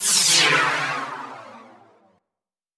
Waka TRAP TRANSITIONZ (40).wav